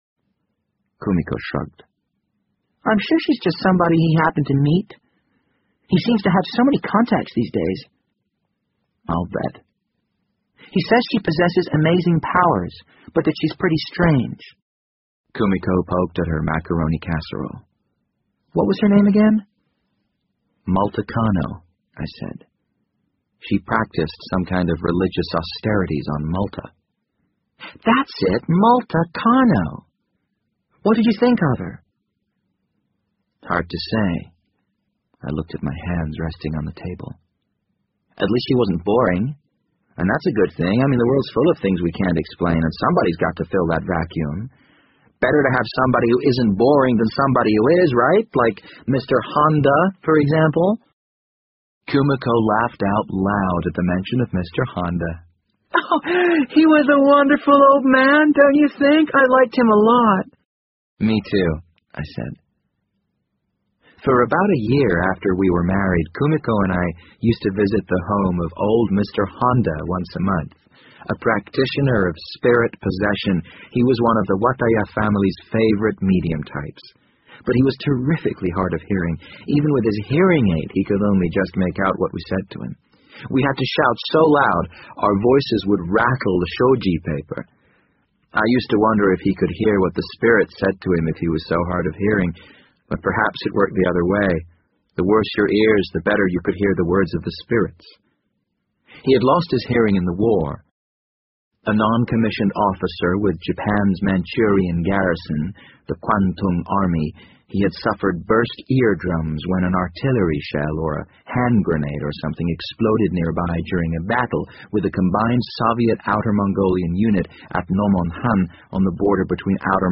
BBC英文广播剧在线听 The Wind Up Bird 23 听力文件下载—在线英语听力室